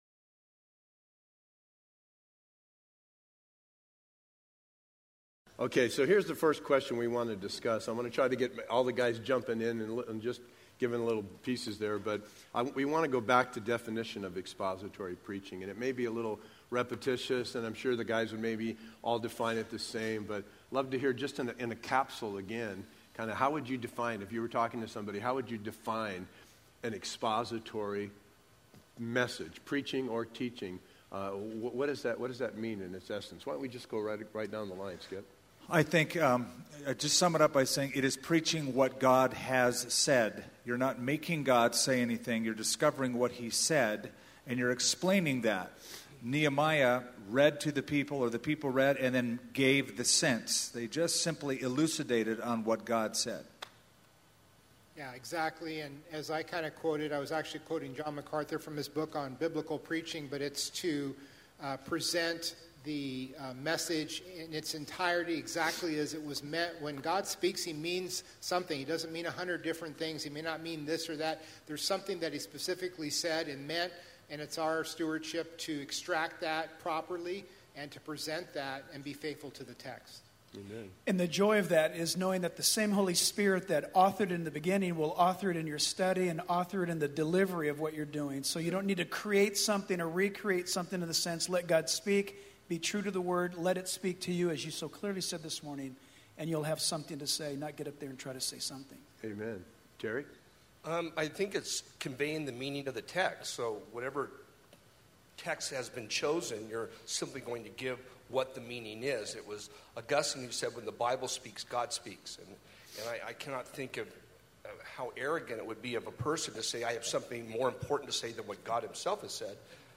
Each pastor answering the relevant questions of expository preaching.